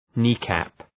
Προφορά
{‘ni:kæp}